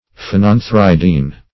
Search Result for " phenanthridine" : The Collaborative International Dictionary of English v.0.48: Phenanthridine \Phe*nan"thri*dine\, n. [Phenanthrene + pyridine.]
phenanthridine.mp3